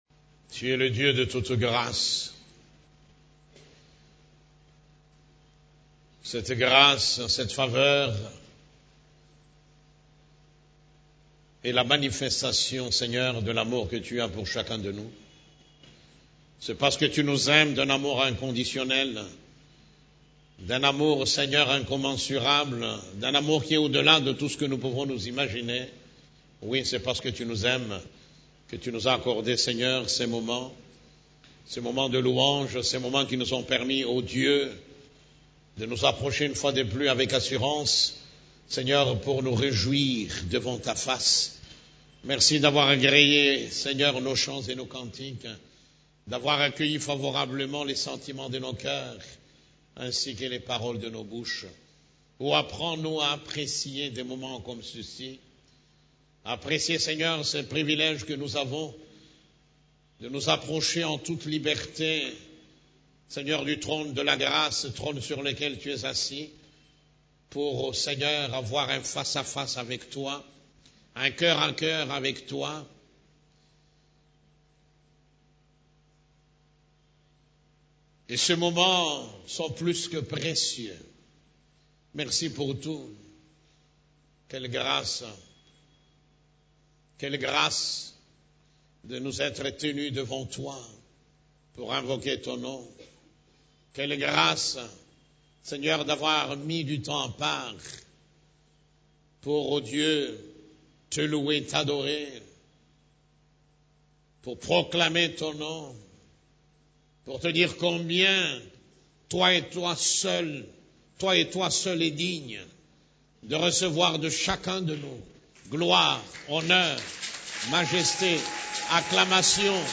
CEF la Borne, Culte du Dimanche, Etre une solution au service des autres